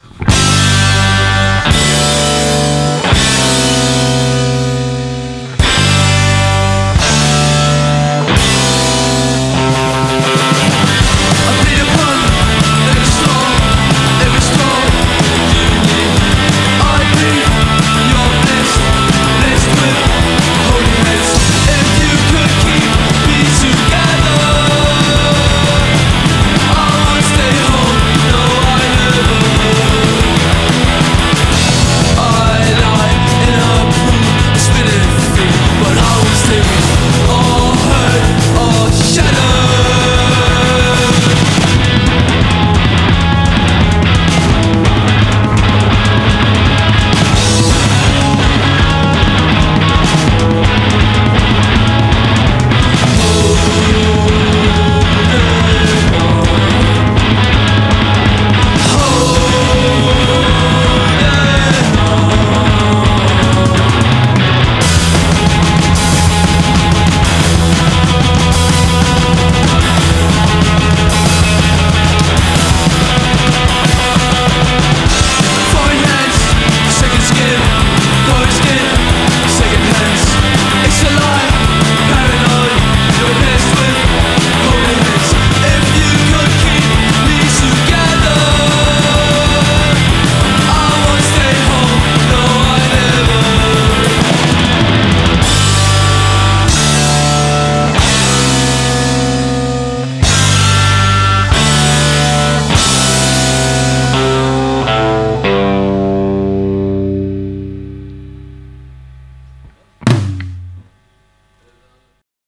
Punk cupo, teso fino allo sfinimento.